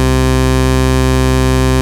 OSCAR 13 C3.wav